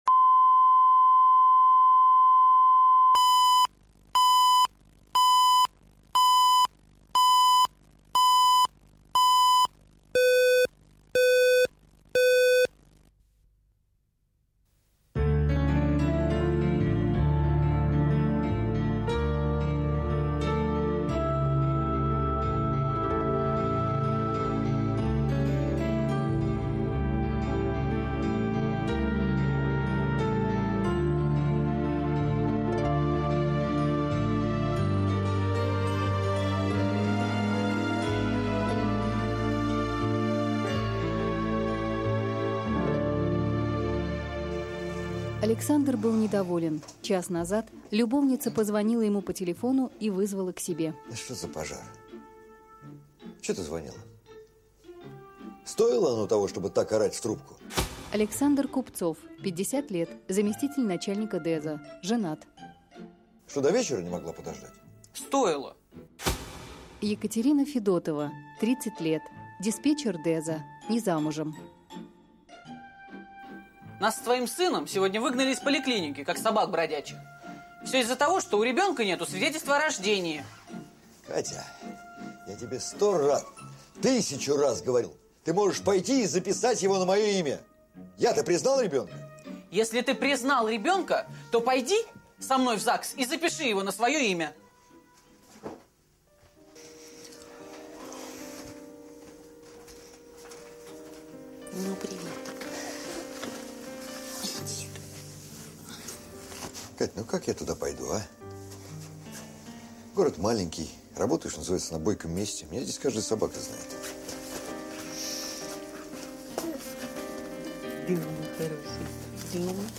Аудиокнига Последняя гастроль | Библиотека аудиокниг